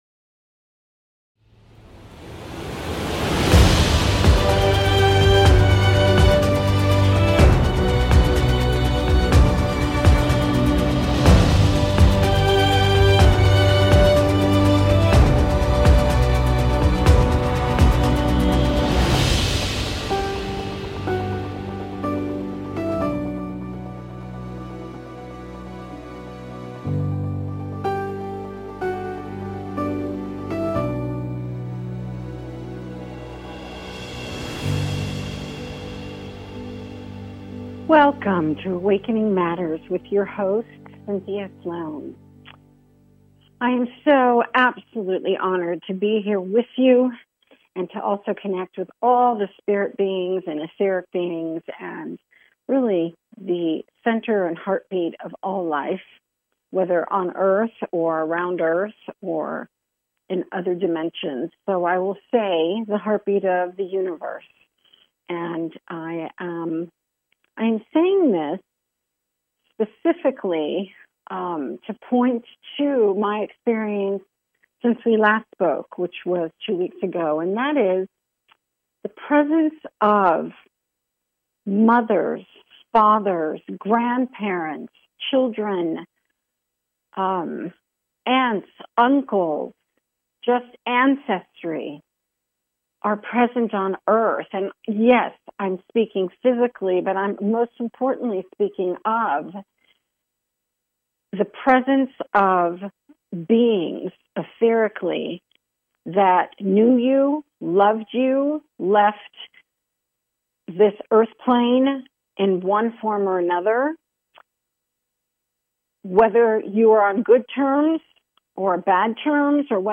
A spiritual dialogue that invites divine wisdom, joy and laughter.